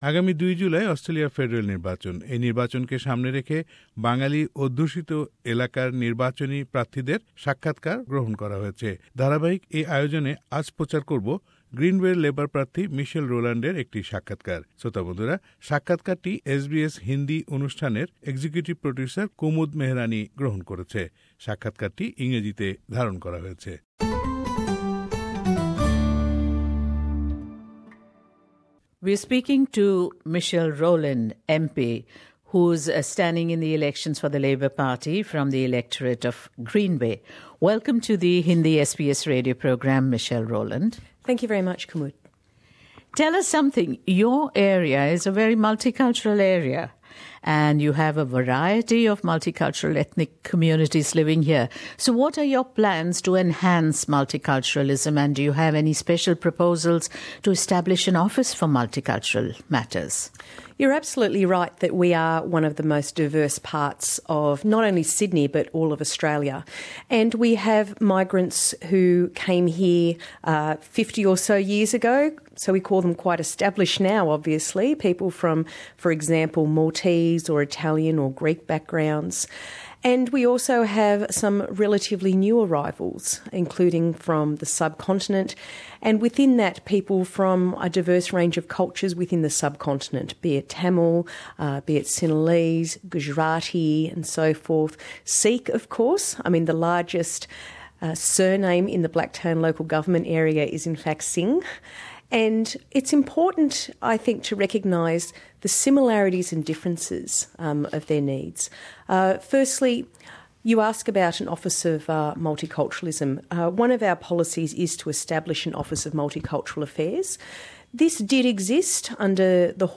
Election 2016: Interview with Michelle Rowland MP